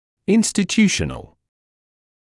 [ˌɪnstɪ’tjuːʃənl][ˌинсти’тйуːшэнл]относящийся к учреждению, организации; институциональный